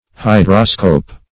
Search Result for " hydroscope" : The Collaborative International Dictionary of English v.0.48: Hydroscope \Hy"dro*scope\, n. [Hydro-, 1 + -scope.] 1.